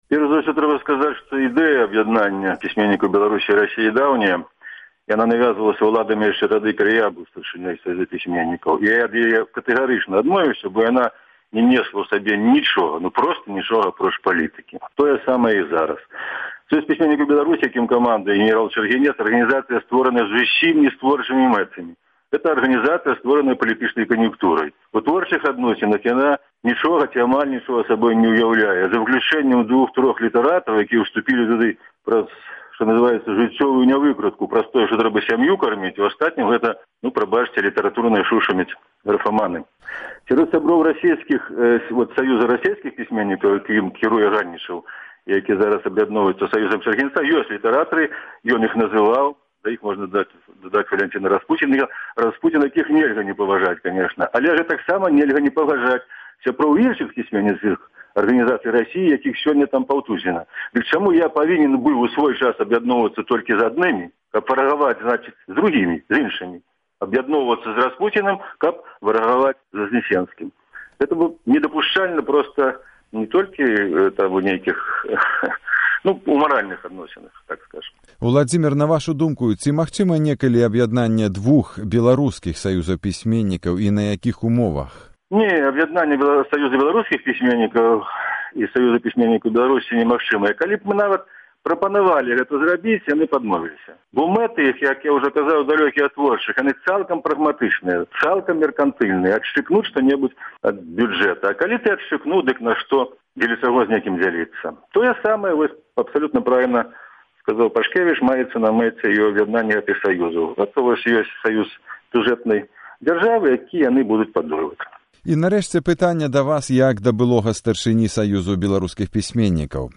Госьць ранішняга эфіру 4 сьнежня 2009 году, вядомы беларускі паэт і празаік Уладзімер Някляеў. Мы спыталіся ў яго, як ён ацэньвае творчы і грамадзкі патэнцыял новага саюзу пісьменьнікаў так званай Саюзнай дзяржавы, які мае быць створаны сёньня?